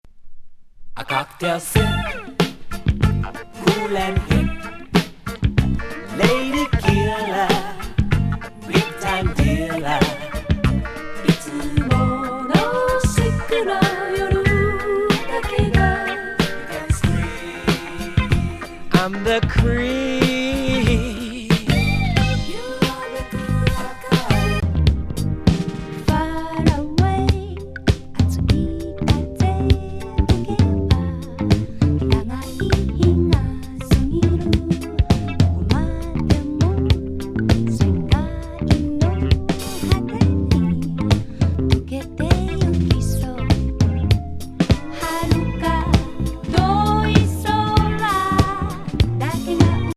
ほんのりエスニックな郷愁和レアリック
チャンキー・グルーブ